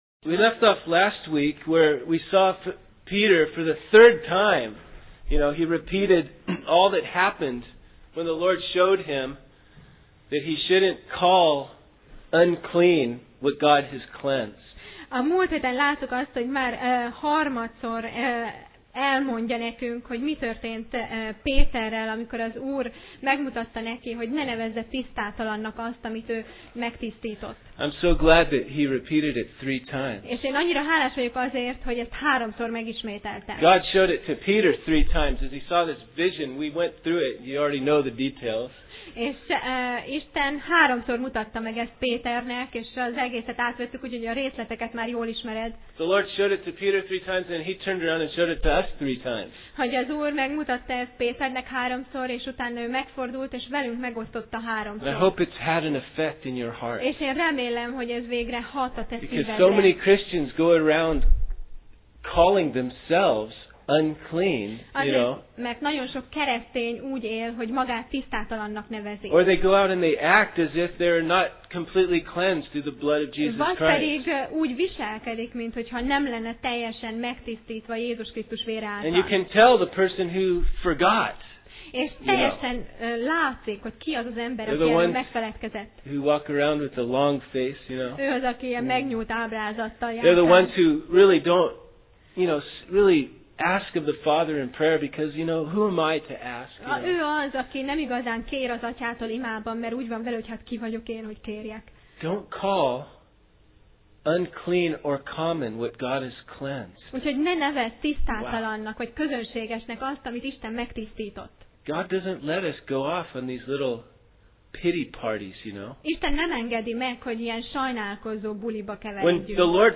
Sorozat: Apostolok cselekedetei Passage: Apcsel (Acts) 11:19-30 Alkalom: Vasárnap Reggel